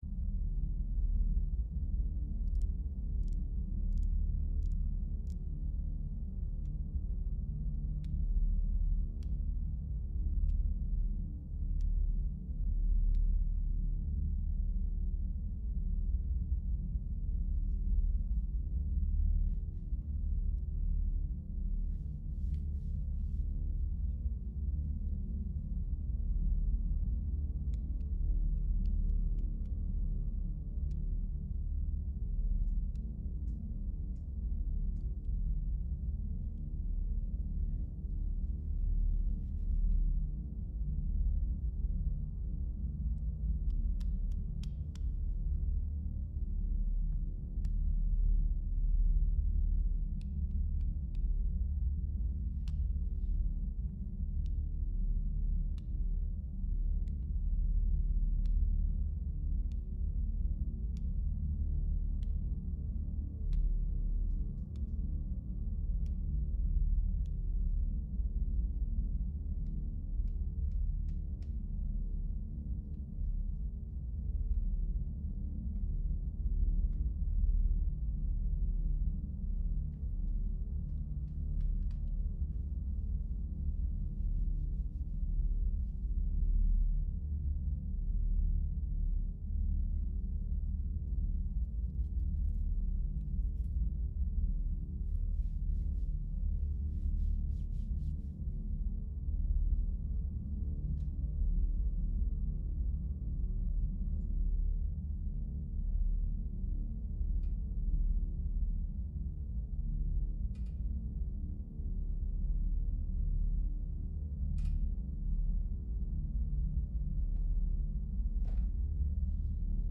amb_打牌氛围.ogg